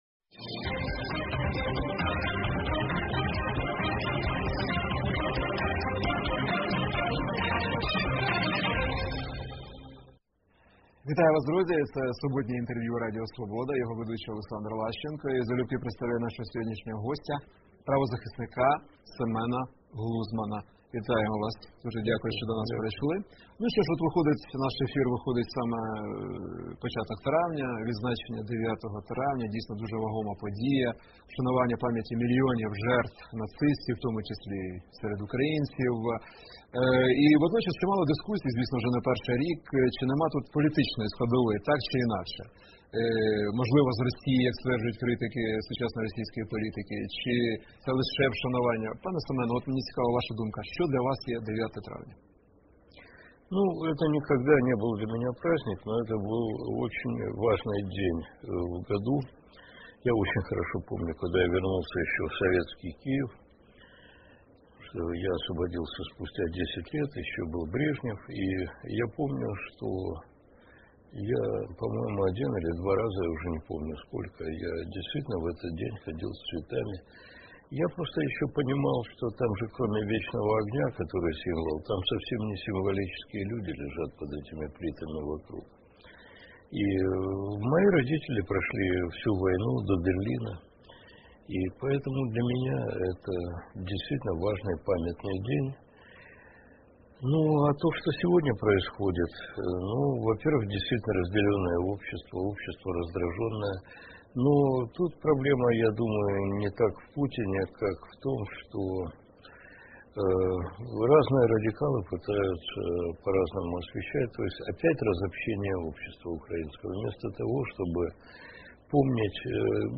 Суботнє інтерв’ю | Семен Глузман, правозахисник
Суботнє інтвер’ю - розмова про актуальні проблеми тижня.